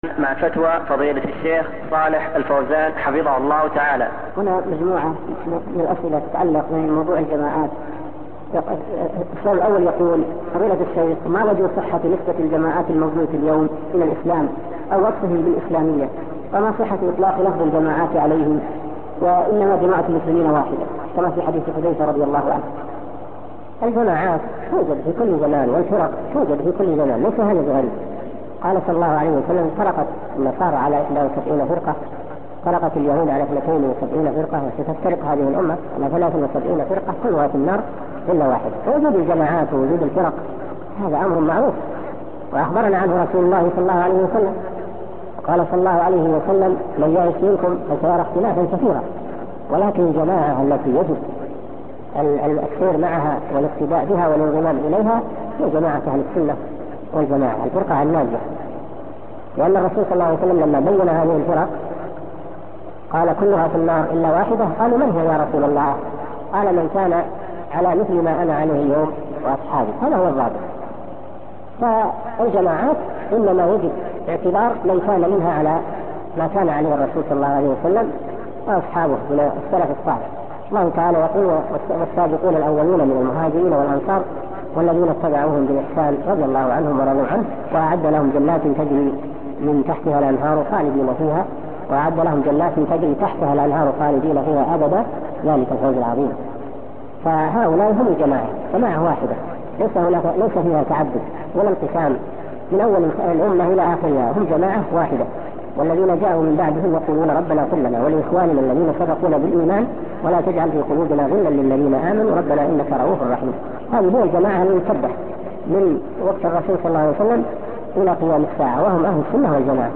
مع فتوى فضيلة الشيخ صالح الفوزان -حفظه الله تعالى-